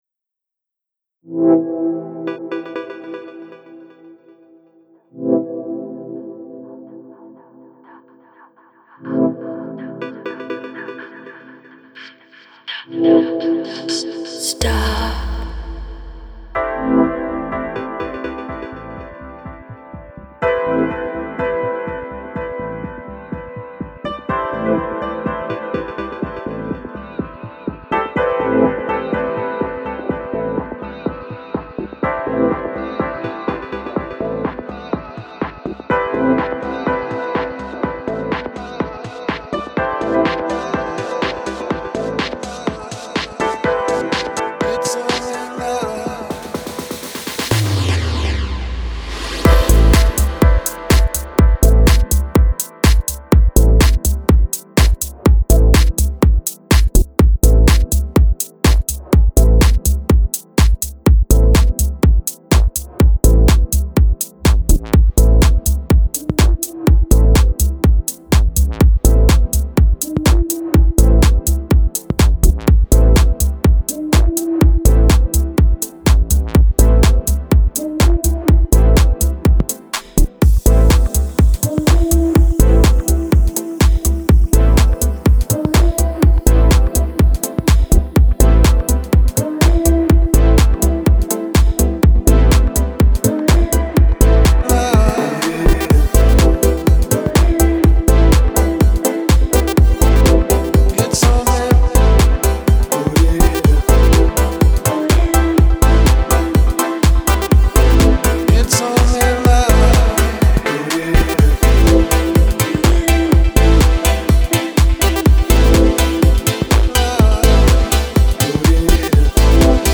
Die Stilrichtung ist unschwer zu erkennen, es ist Elektronische Musik mit einem deutlichen Hang zu Progressive House mit Trance-Elementen (mit Ausnahmen).
Heute macht man alles am Computer und kann an jedem Detail beliebig lange feilen. In den Stücken kommen oft 30 und mehr Spuren zusammen.